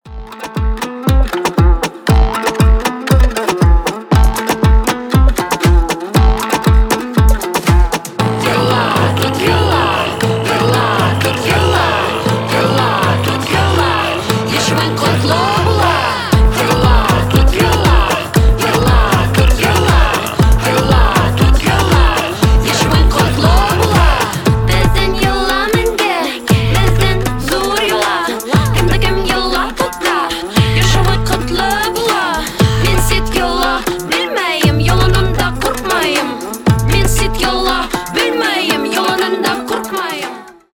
фолк , инструментальные
этнические